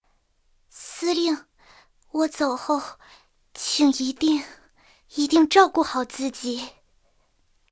I号被击毁语音.OGG